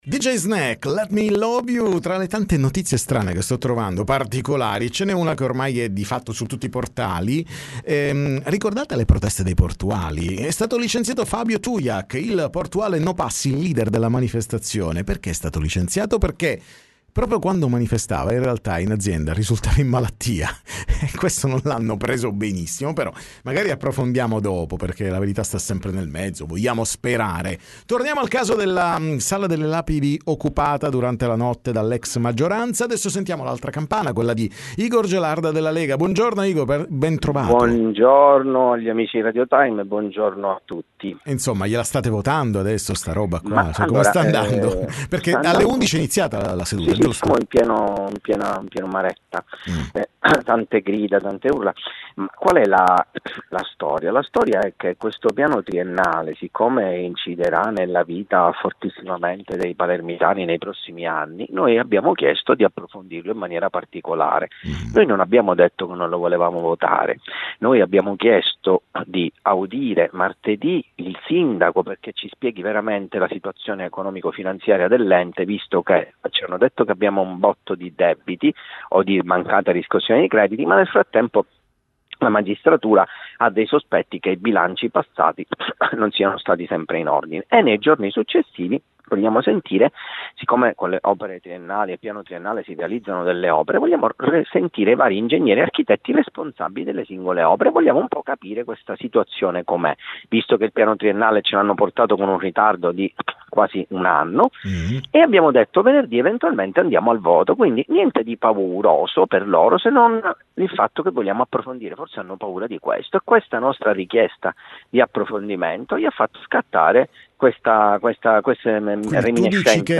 TM Intervista Igor Gelarda